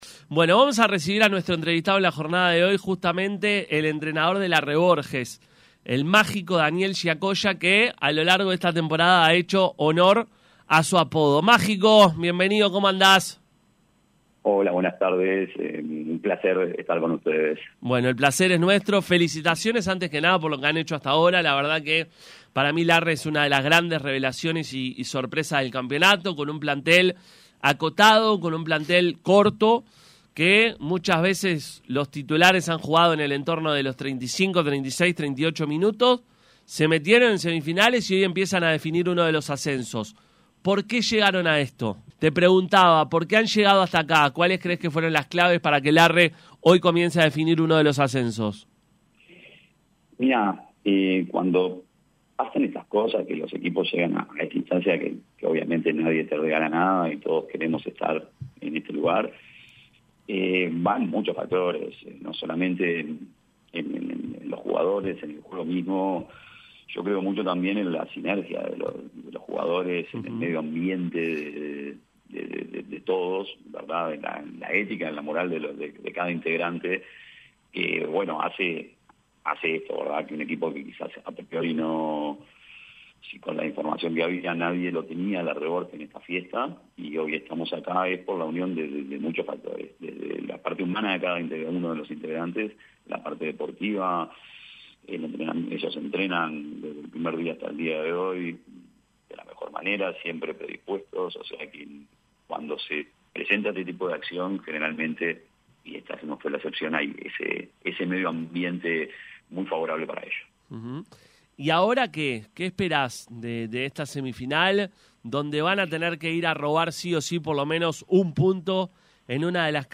habló con Pica La Naranja previo al comienzo de las semifinales ante Atenas.